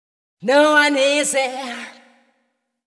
House / Voice / VOICEGRL095_HOUSE_125_A_SC2.wav